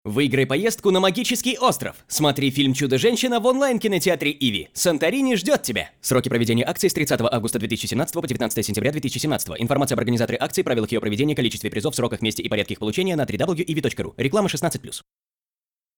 Тракт: Профессиональная студия
Демо-запись №1 Скачать